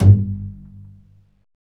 Index of /90_sSampleCDs/Roland LCDP13 String Sections/STR_Cbs FX/STR_Cbs Pizz